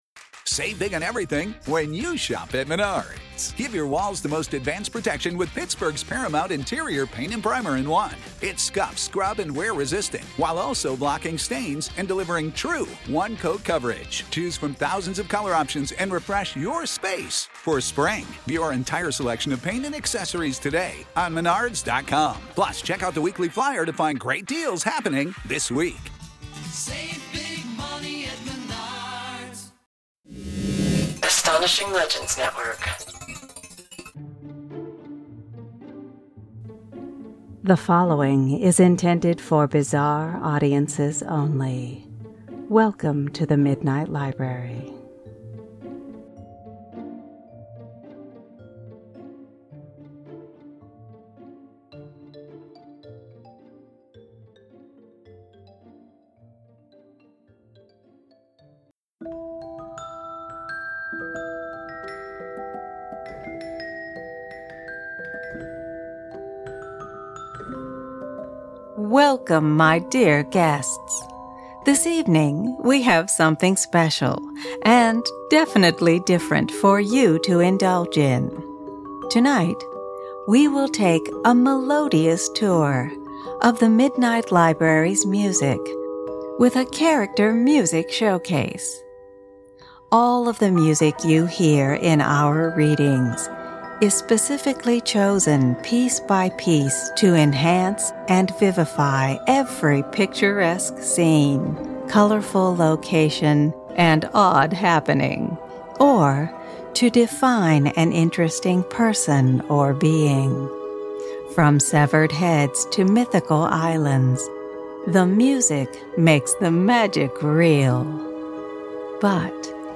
Join me for 40 melodious minutes of music and polite talk about how and why each piece was chosen for the beloved characters and unique places found only in the world of The Midnight Library! This will be followed by a short Q&A session.